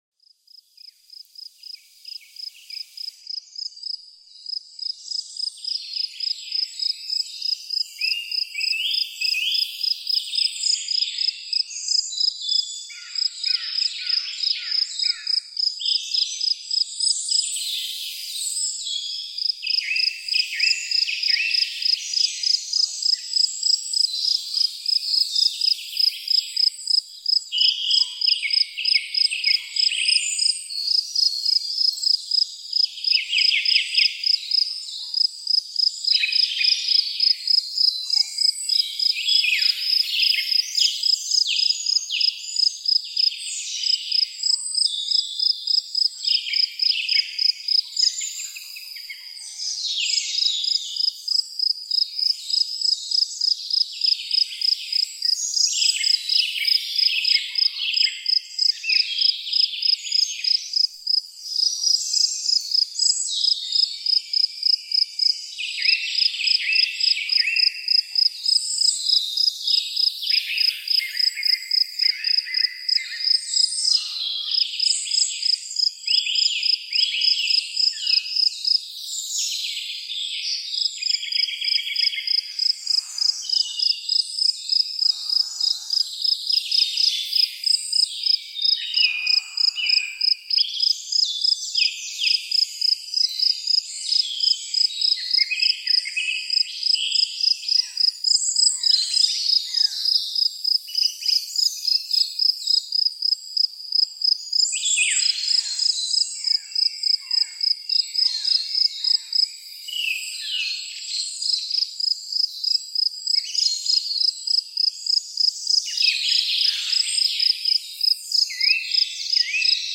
EINSCHLAF-FORMEL: Sommerabend-Geheimnis mit Grillen + Vögeln